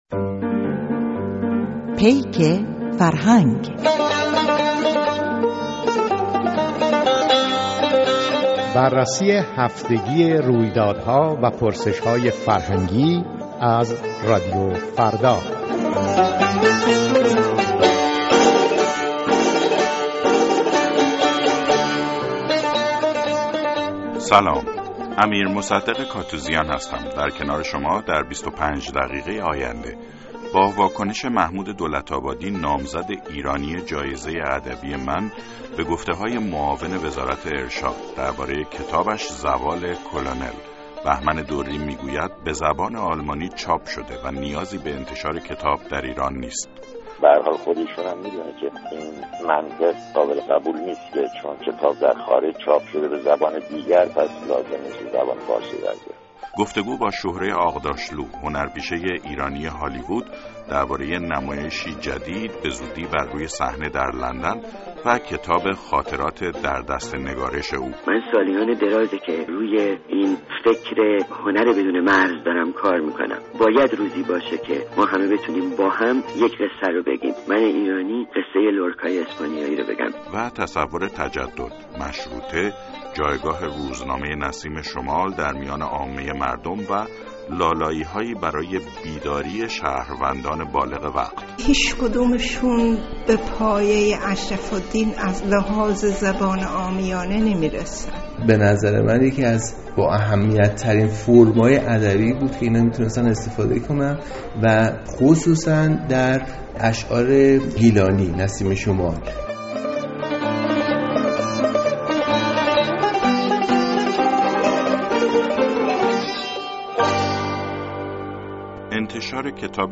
مصاحبه با شهره آغداشلو در پیک فرهنگ را بشنوید